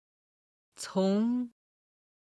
从　(cóng)　・・から